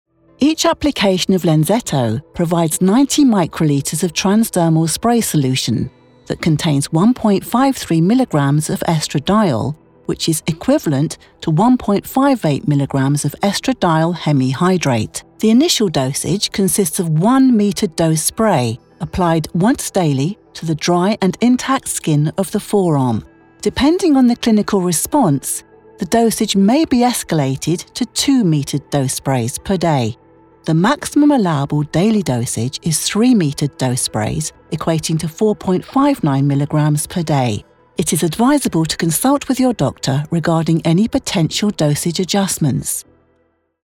Female
English (British)
Medical Narrations
Technical Medical Narration
Words that describe my voice are Approachable, Reassuring, Compassionate.